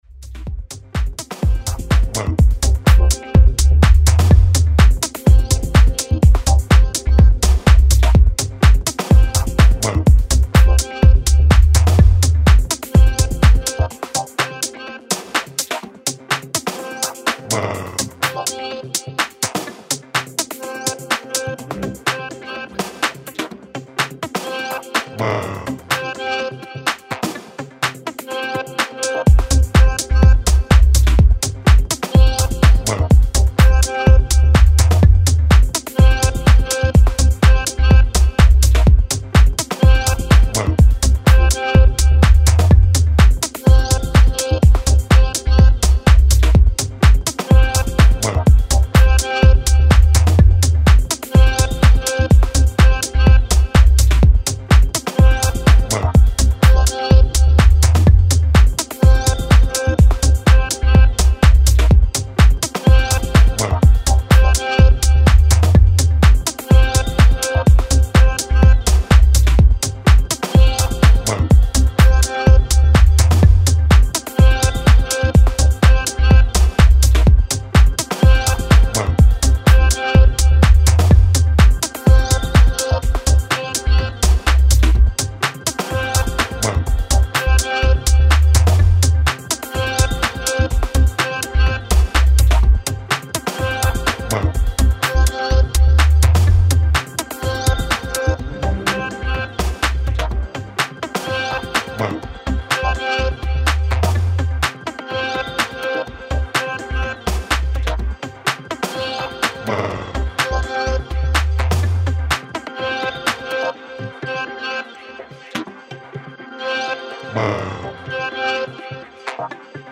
a lean groove working the very long hours
Nerve tingling goodness for intimate listening experiences.